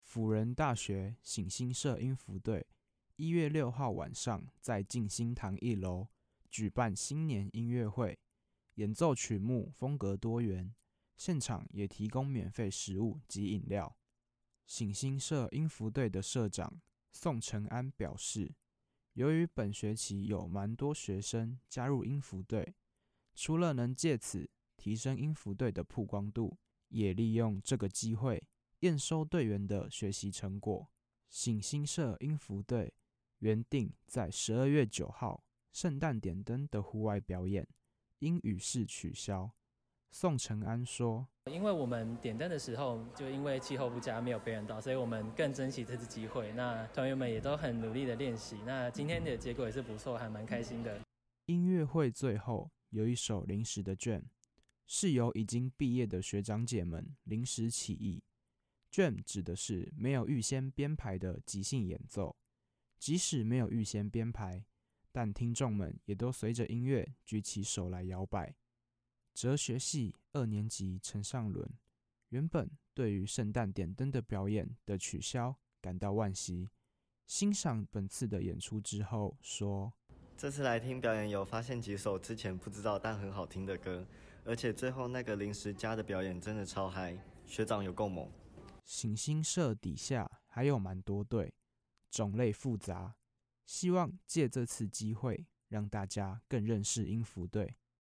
輔仁大學醒新社音符隊一月六號晚上在淨心堂舉辦新年音樂會，現場也提供免費點心，也藉此彌補聖誕市集演出取消的遺憾，音樂會的最後也有一場由畢業的學長姐們表演的jam。